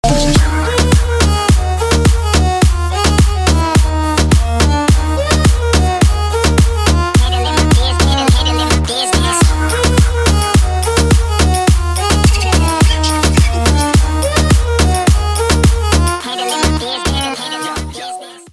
• Качество: 128, Stereo
dance
Electronic
восточные
турецкие